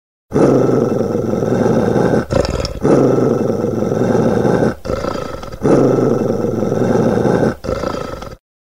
Звуки собак
Звук злой собаки во время еды при приближении человека